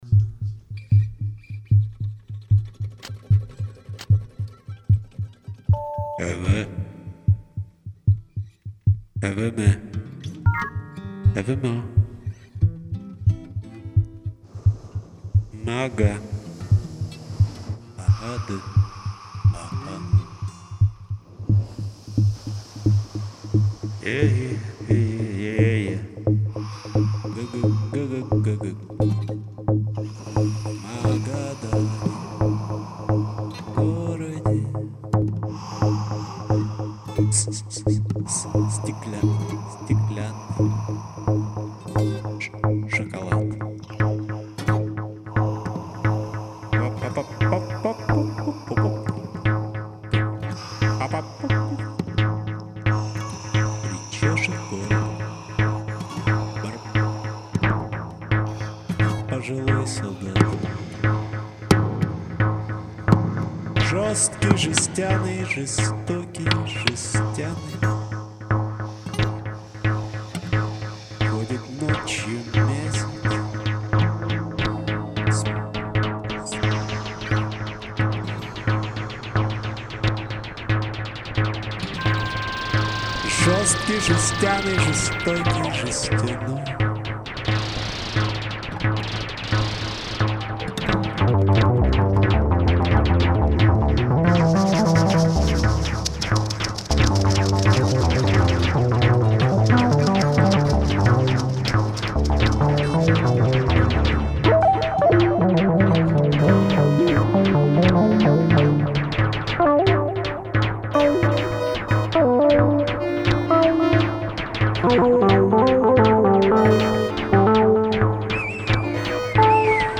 voix, guitare, machines
machines, voix